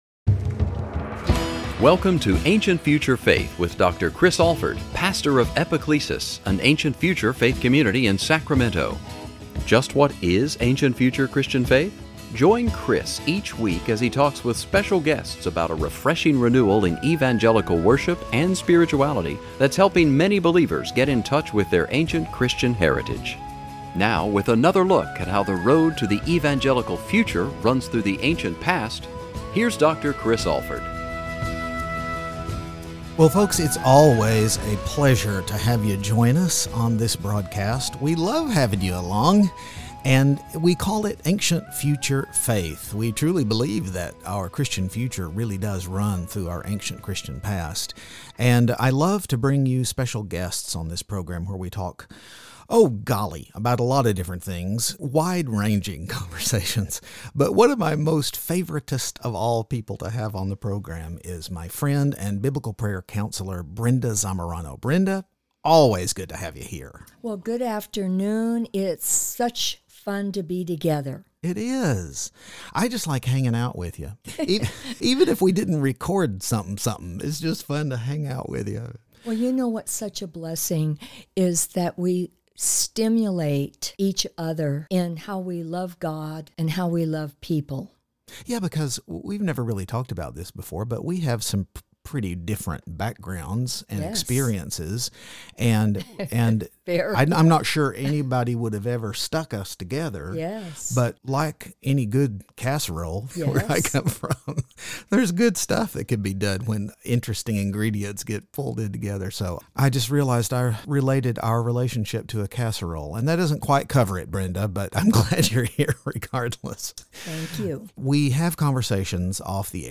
Join us for a wonderful conversation!